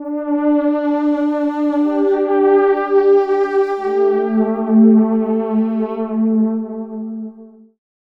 Session 14 - Pad.wav